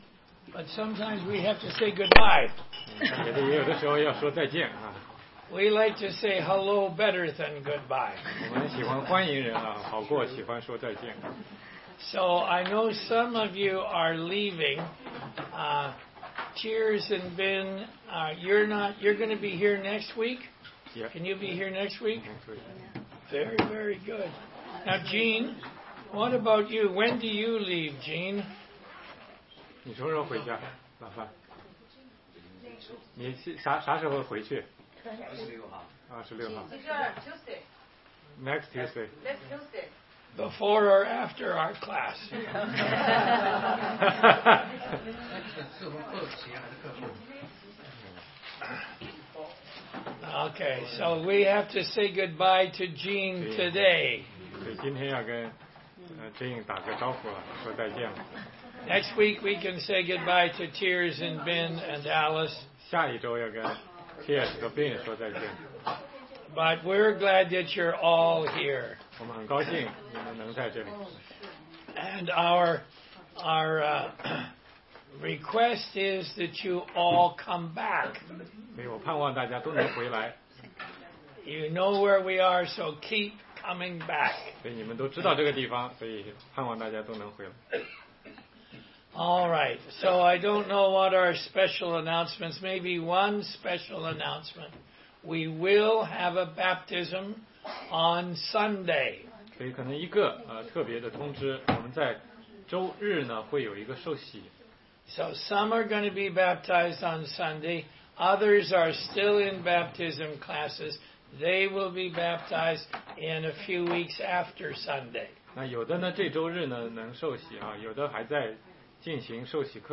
16街讲道录音 - 罗马书4章